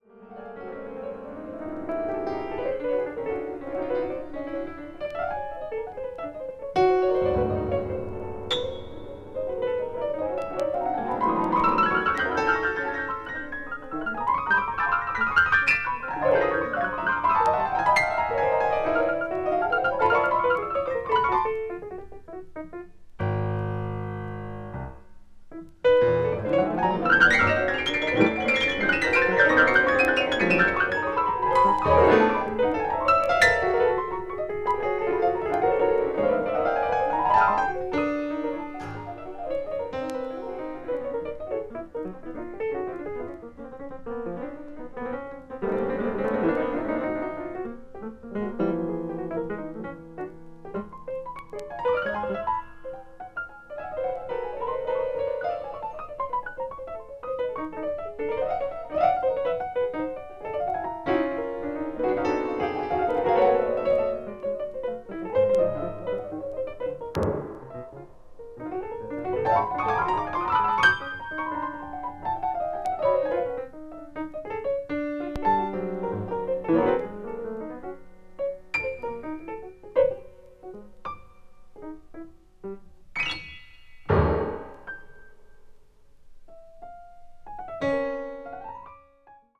avant-garde   contemporary   modern classical   post modern